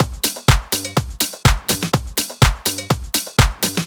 • good snare house drum loop.wav
good_snare_house_drum_loop_GNX.wav